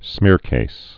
(smîrkās)